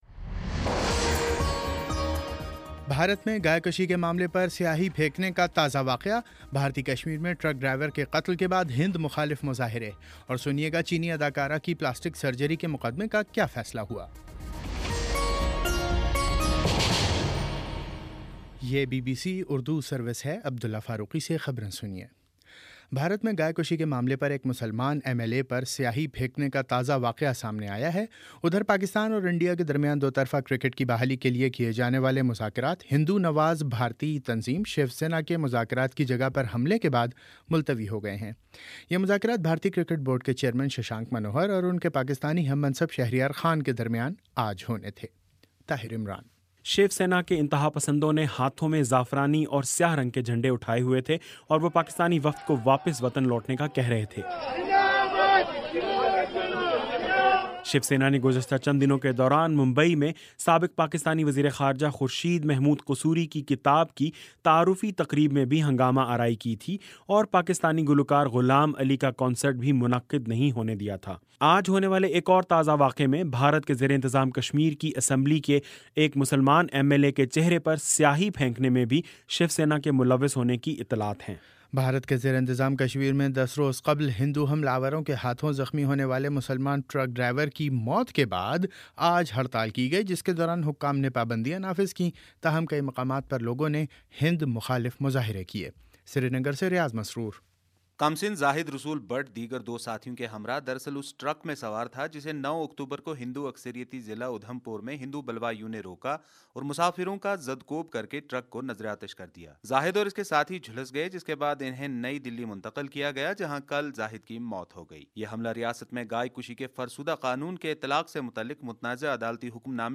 اکتوبر 19 : شام سات بجے کا نیوز بُلیٹن